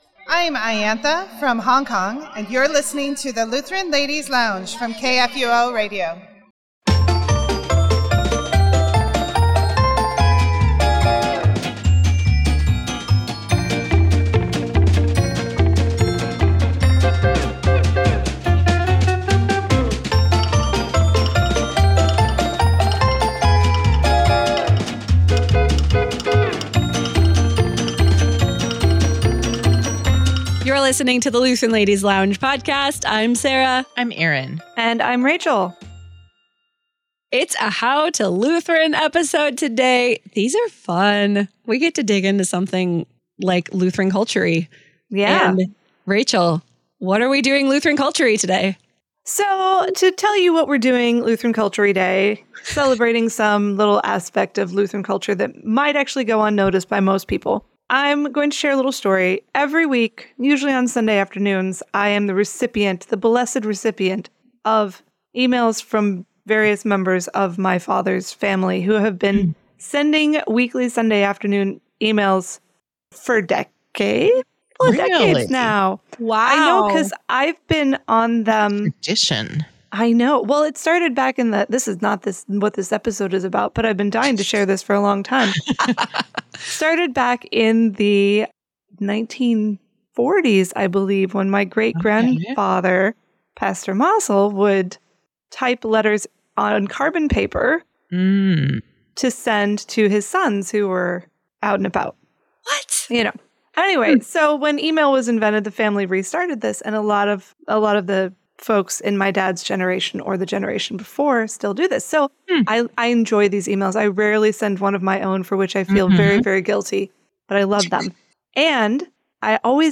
In their latest “How to Lutheran” conversation